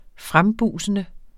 frembusende adjektiv Bøjning -, - Udtale [ -ˌbuˀsənə ] Oprindelse jævnfør buse Betydninger ivrig, men ubehersket og tankeløs i sin opførsel Antonym forsigtig Se også buse frem frembrusende du skal være ydmyg.